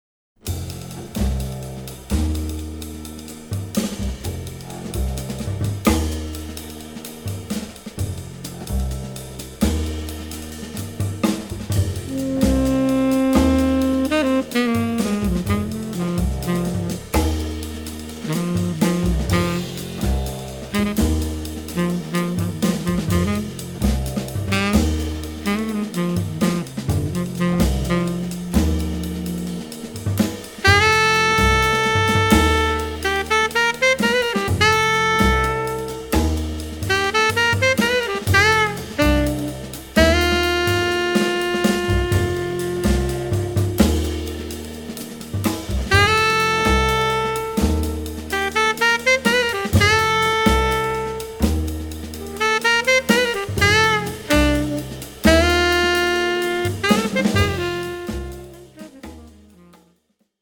impressive post-bop offering
Genre: Jazz.
tenor saxophone
bass
drums